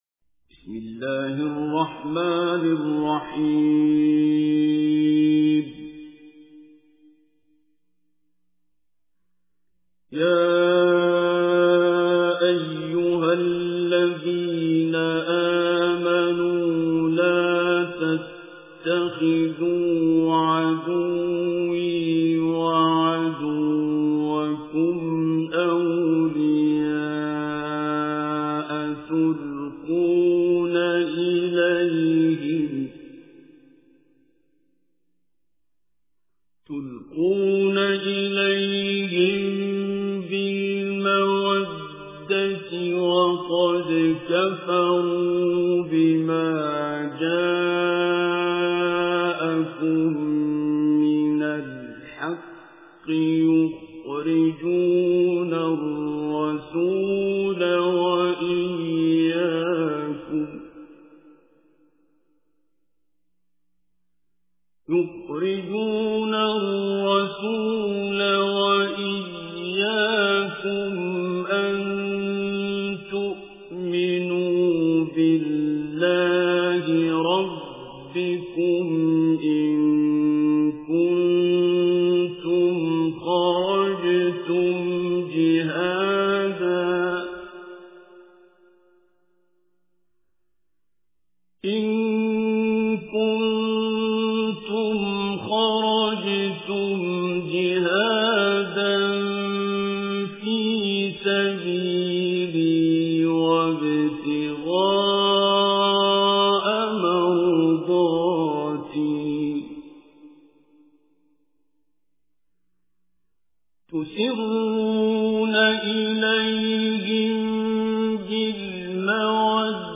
Surah Mumtahinah Recitation with Urdu Translation
Surah Al-Mumtahinah is 60th chapter or Surah of Holy Quran. Listen online and download mp3 tilawat / recitation of Surah Al-Mumtahinah in the beautiful voice of Qari Abdul Basit As Samad.